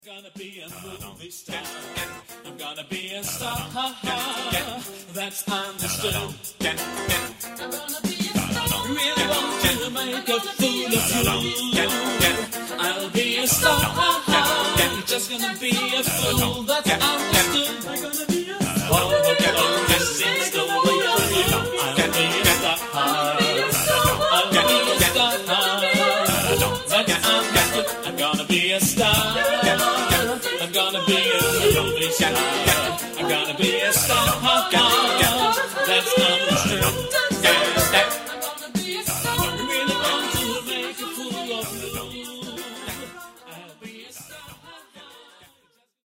Genre-Style-Forme : Canon ; Funk ; Profane
Type de choeur : FM  (2 voix mixtes )
Tonalité : sol majeur